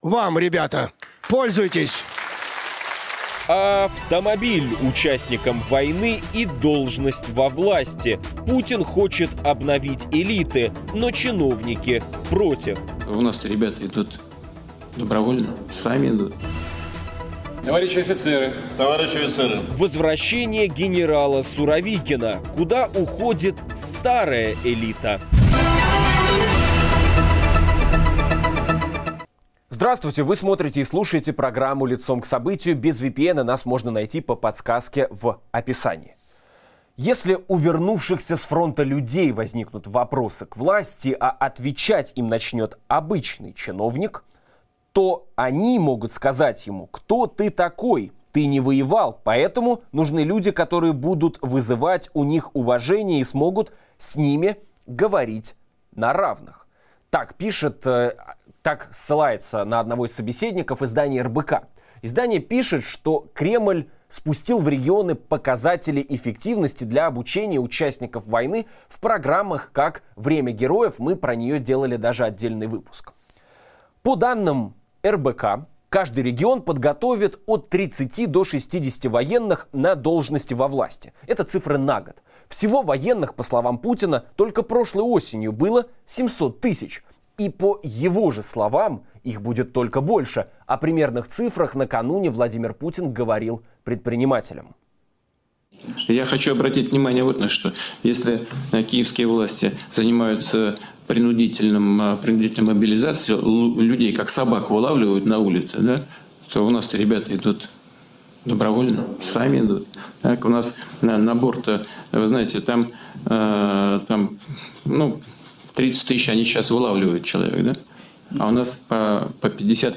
Путину нужны во власти ветераны "СВО", чтобы заменить военную элиту, которая потеряла доверие? Обсуждаем с политиком Дмитрием Гудковым